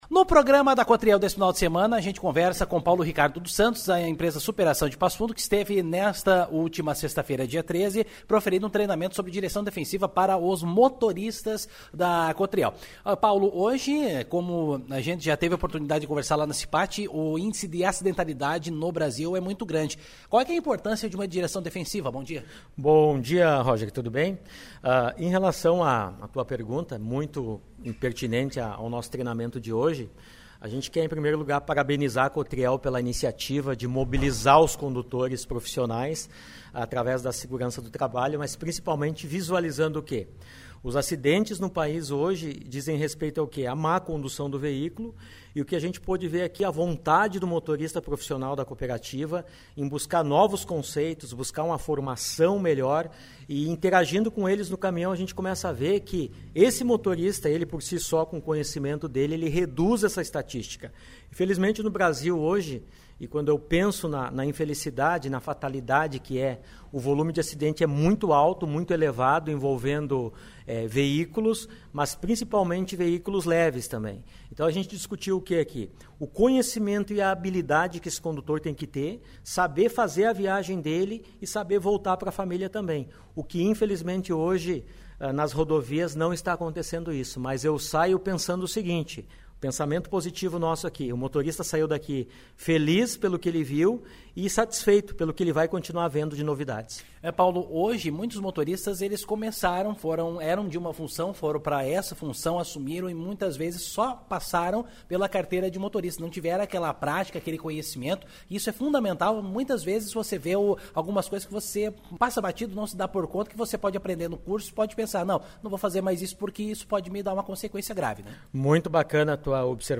aqui a entrevista.